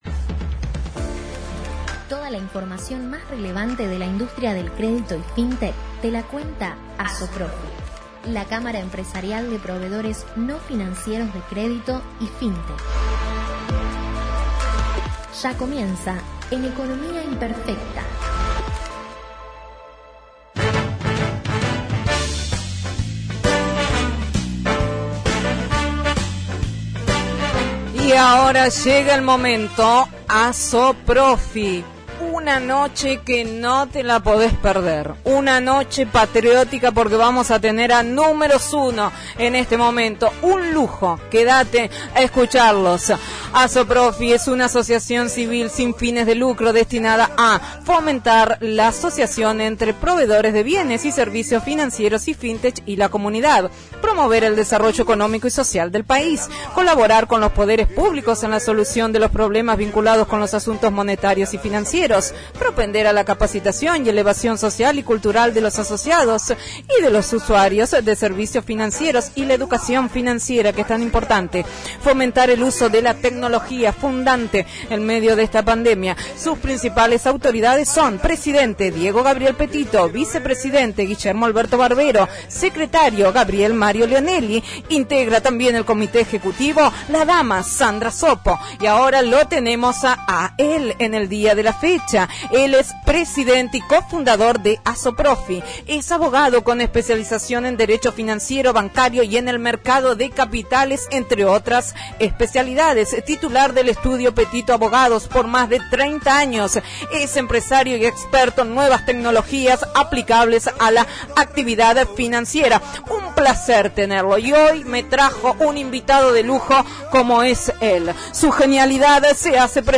Para volver a escuchar la Columna Radial ingresando aquí: